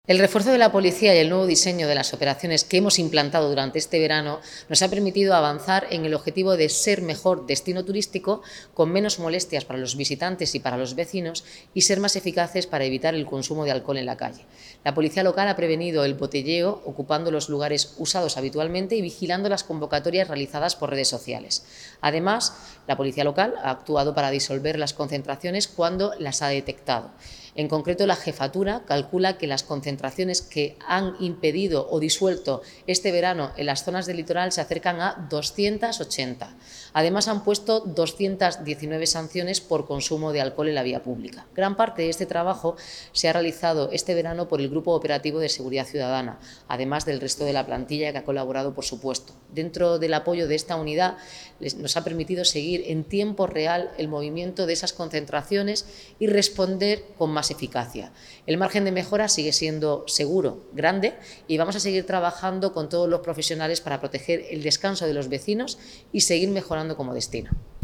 Enlace a Declaraciones Noelia Arroyo